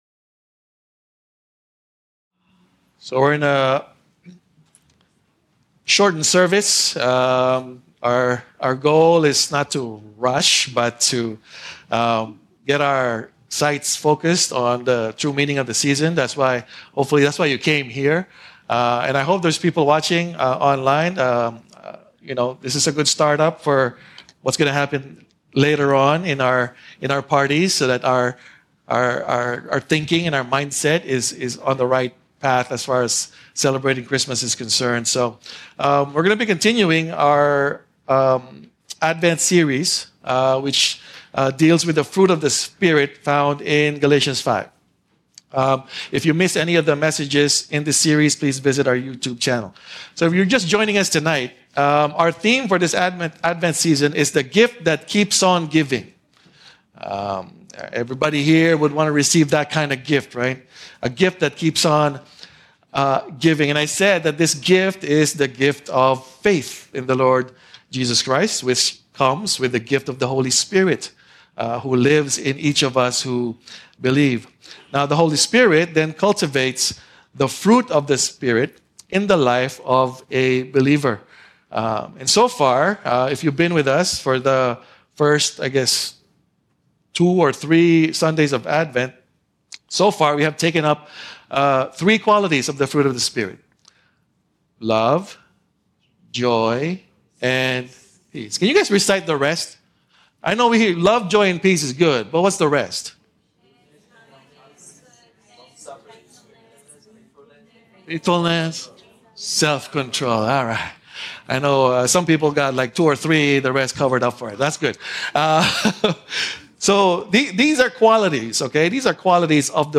He distinguishes between spiritual gifts and spiritual fruit, explaining that while gifts are what we do, the fruit represents who we are in Christ. This sermon emphasizes that these qualities are perfectly embodied in Jesus alone, and through the Holy Spirit, believers are shaped into His likeness to bless those around them.